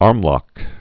(ärmlŏk)